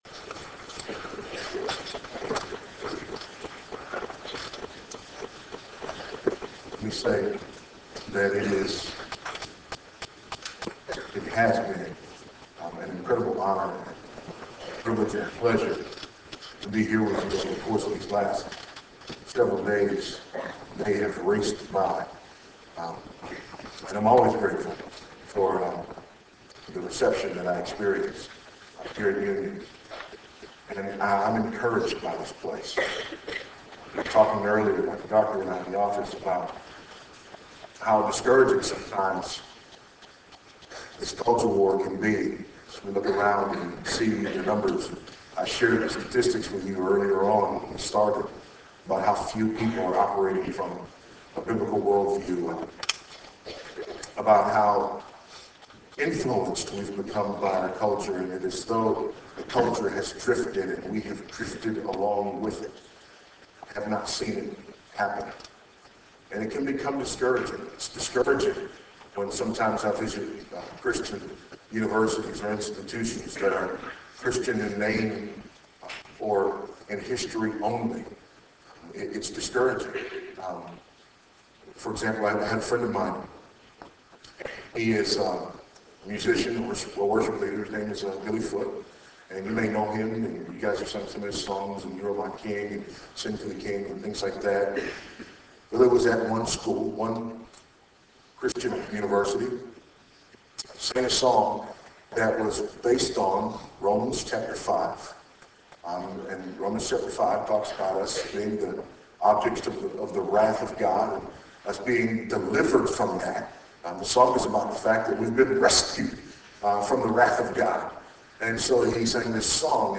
Faith in Practice Conference Session 3